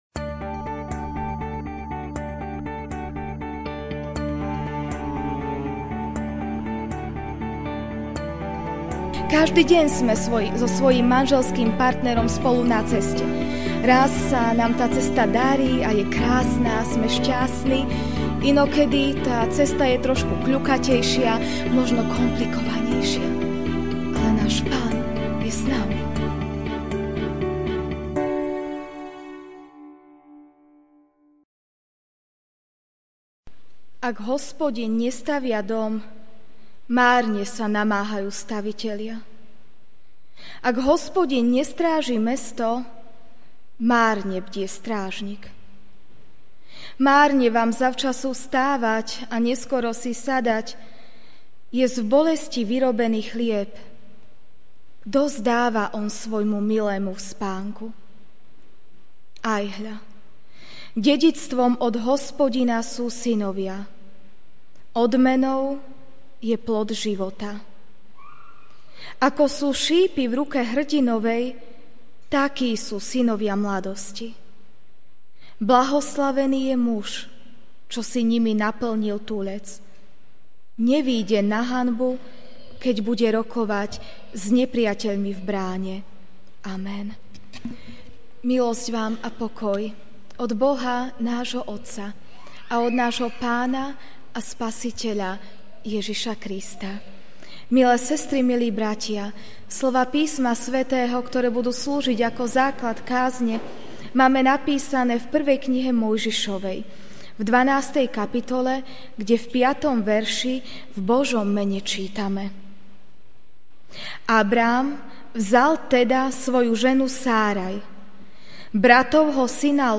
Večerná kázeň: Spolu na ceste: Abrahám a Sára (1.